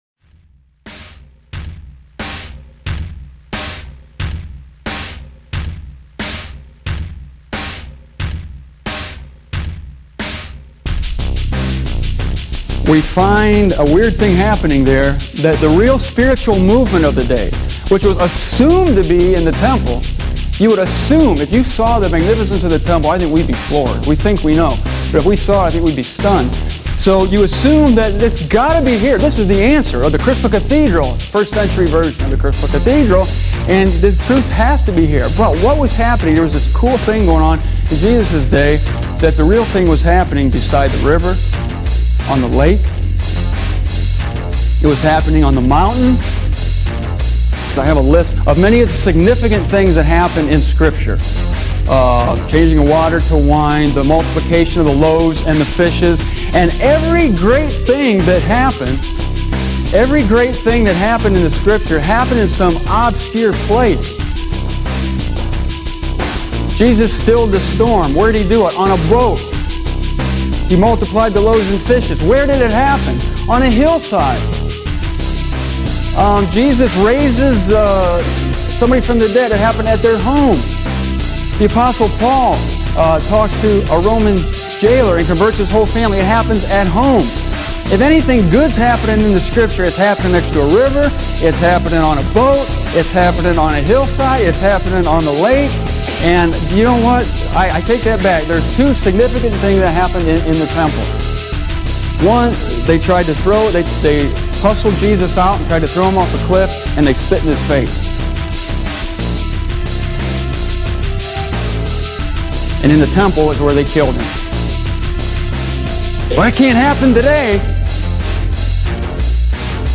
Not many people showed up.